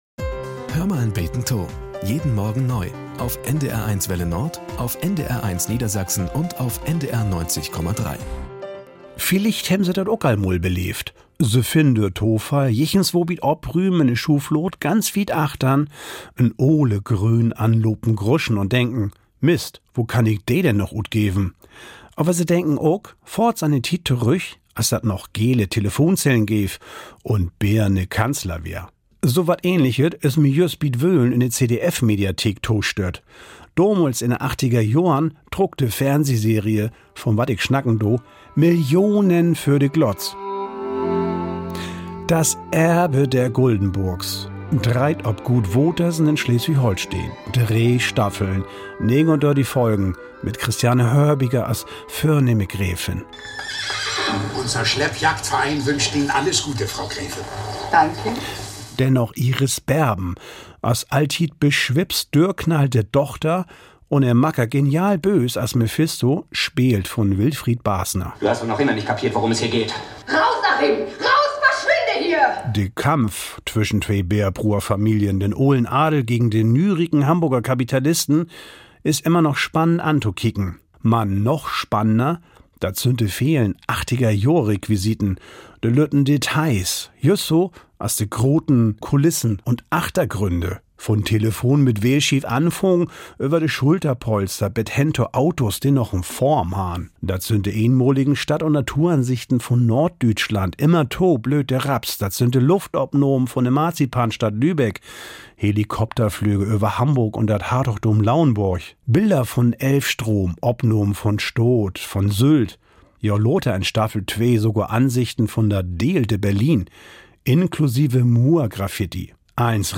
Nachrichten - 01.08.2023